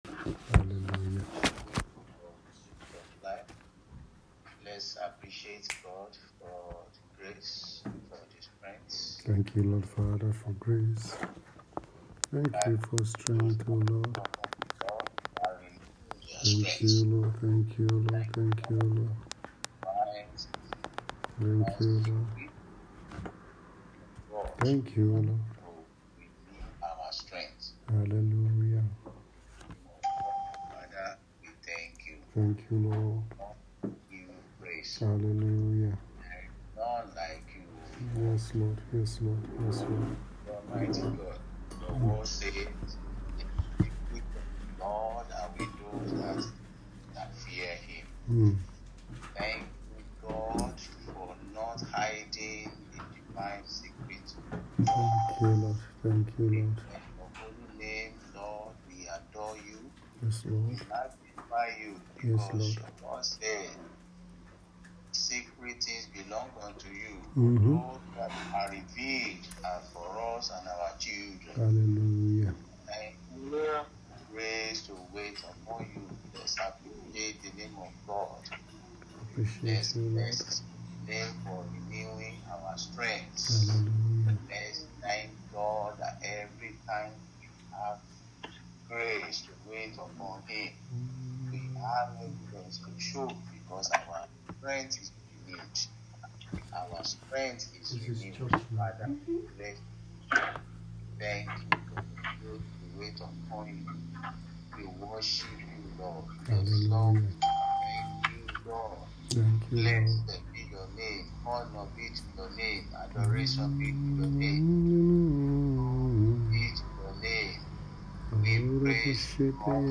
Passage: Philippians 3:10 Bible teaching
during the mid-year fasting week 2021